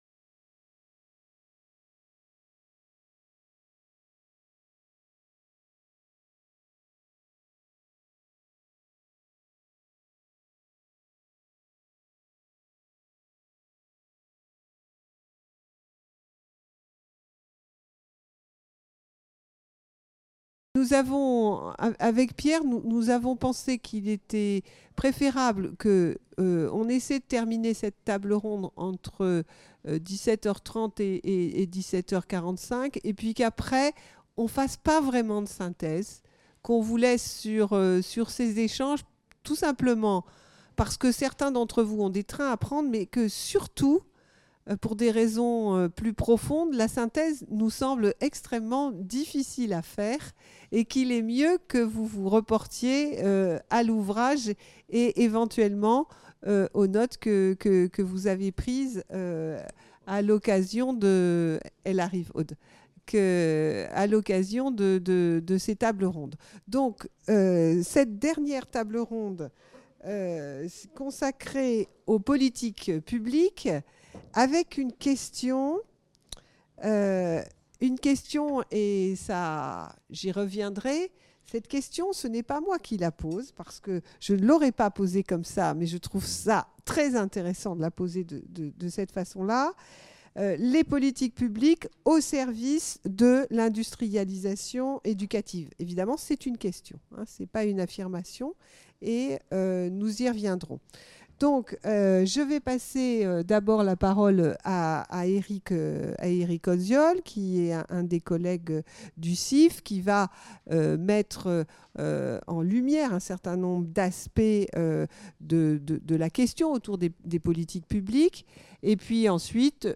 L’industrialisation de l’éducation 4e table ronde : Les politiques publiques au service de l’industrialisation éducative ?